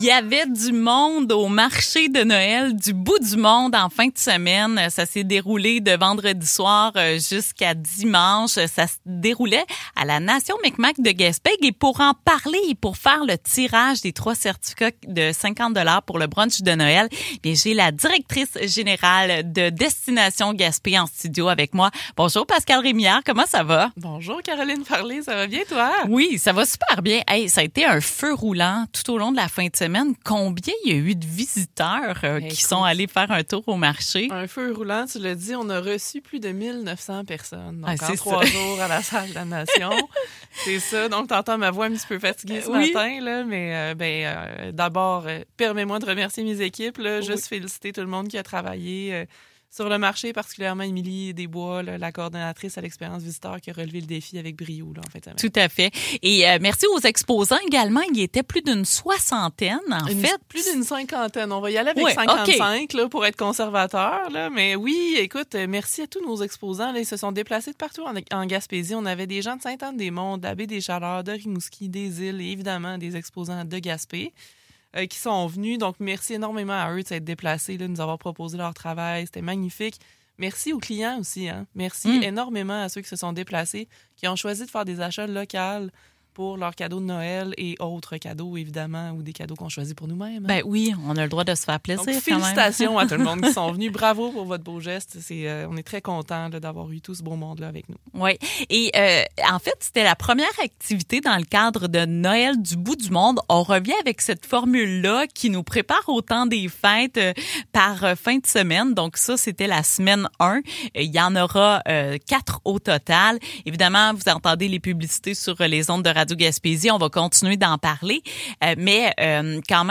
Le tirage des trois certificats-cadeaux de 50$ a également été effectuée lors de l’entrevue.